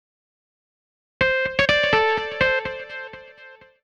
XTRA019_VOCAL_125_A_SC3.wav